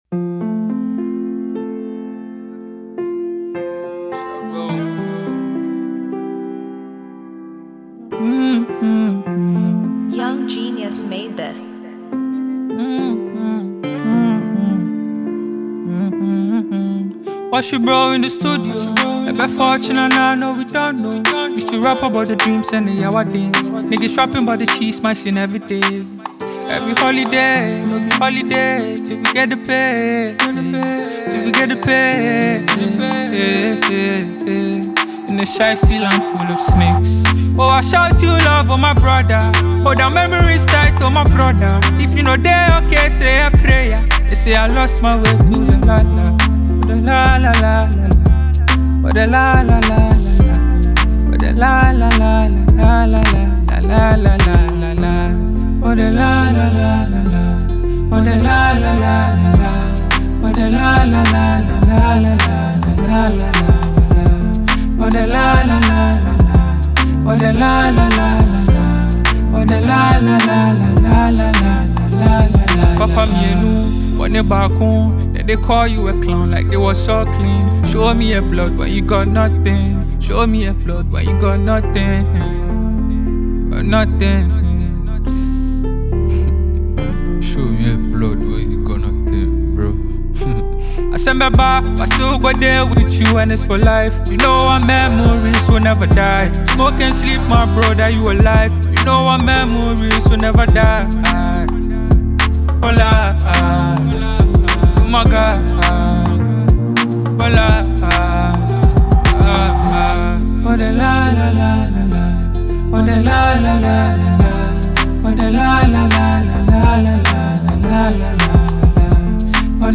Ghana